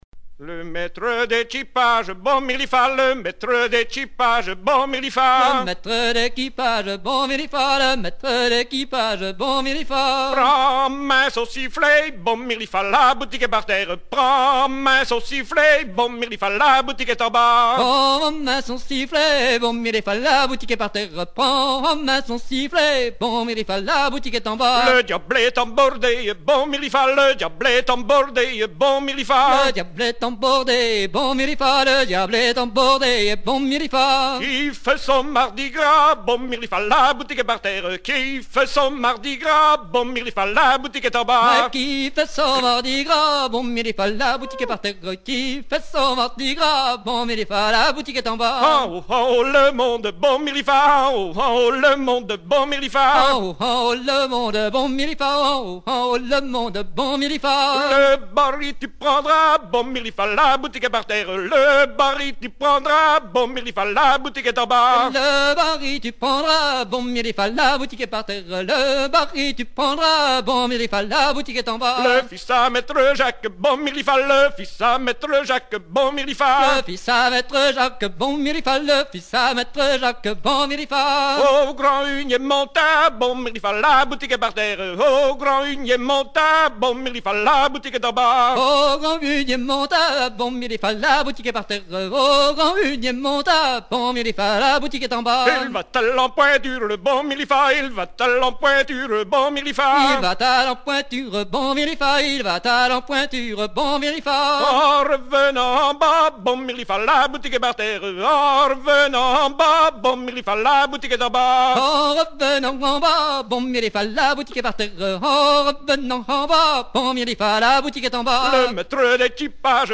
Chant composé sur un timbre traditionnel
Genre laisse